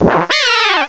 cry_not_purugly.aif